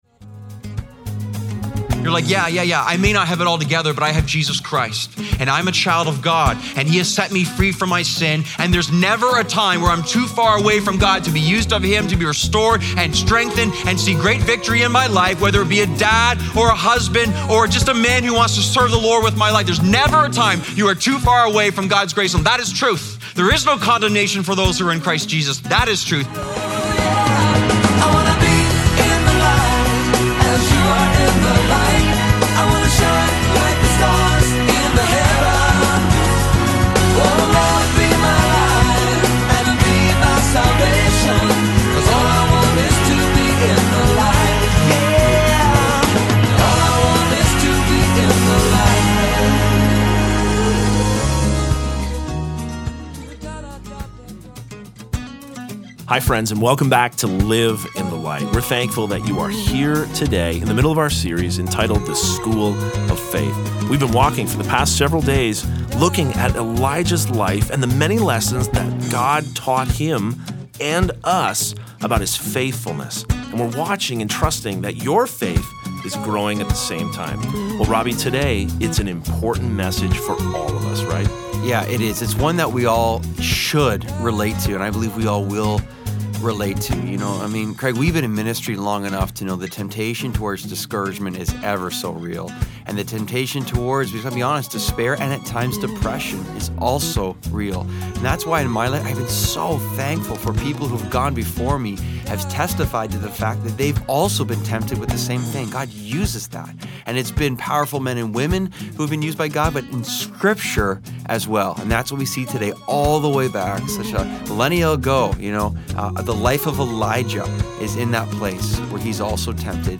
Listen to daily messages